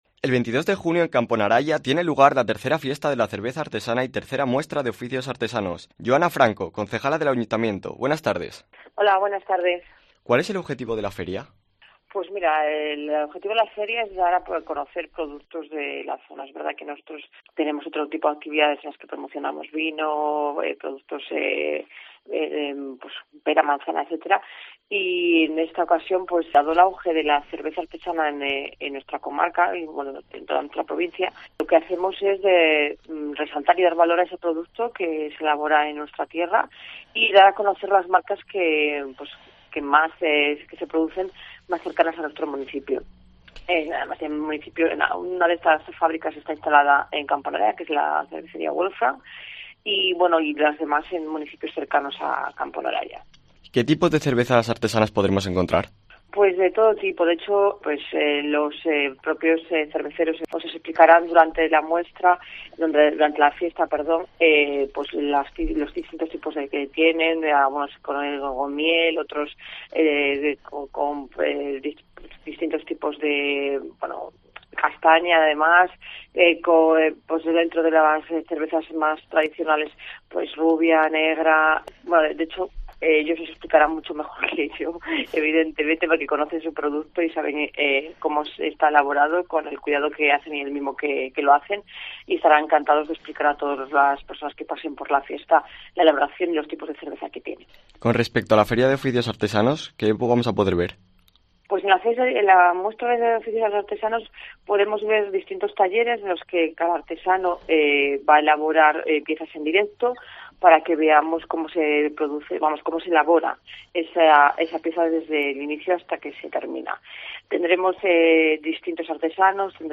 En Herrera y Mediodía en Cope León hablamos con Yoana Franco, concejala del Ayuntamiento de Camponaraya.